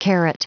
Prononciation du mot caret en anglais (fichier audio)
Prononciation du mot : caret